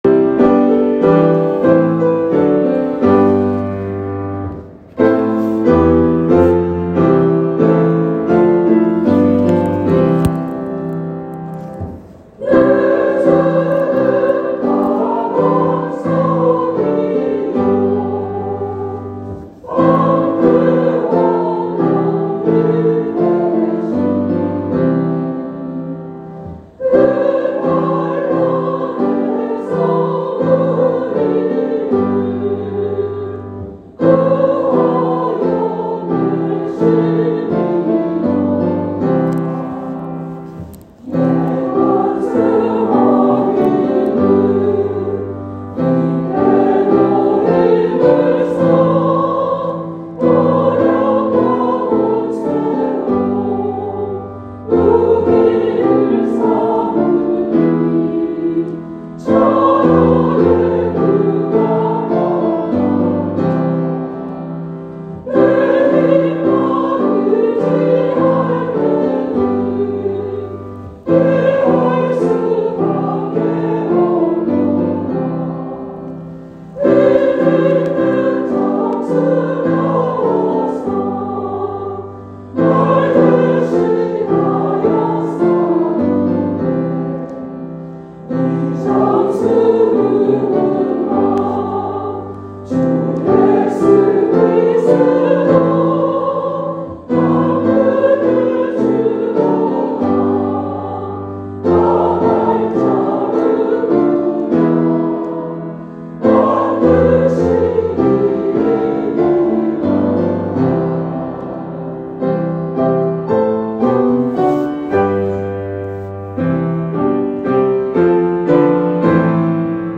찬양대
종교개혁주일 504주년 기념예배